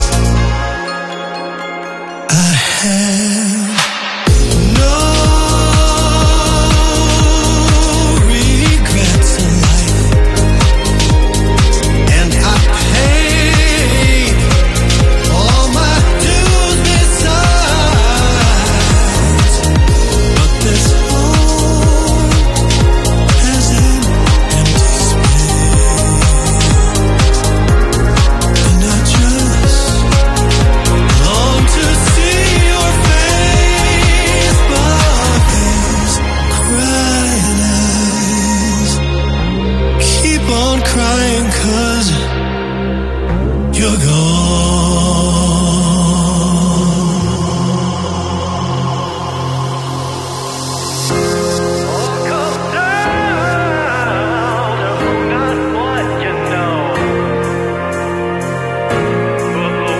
dance remixes
strings and orchestration